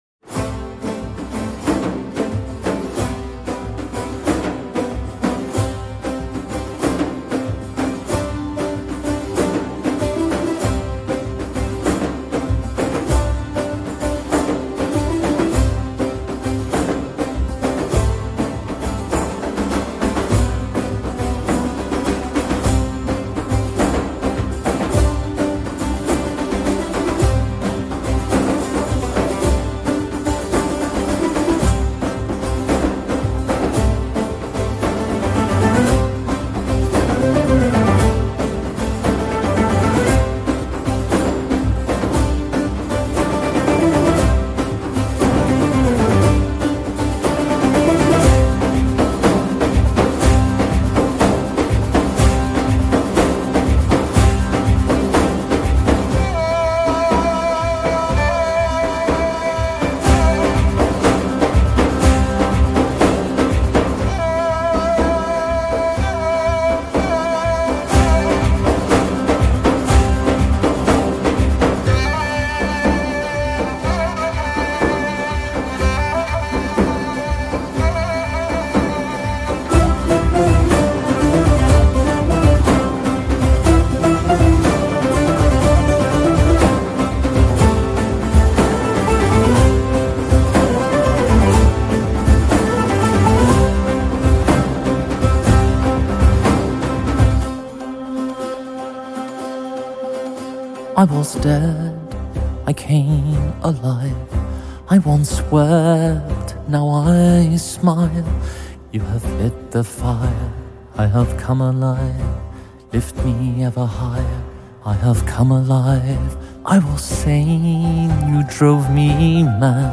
سنتی